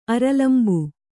♪ aralambu